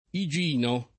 iJ&no] o Iginio [iJ&nLo] pers. m. — solo Igino quale nome storico: così, oltre a un santo papa (sec.